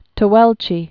(tə-wĕlchē, tā-wĕlchā)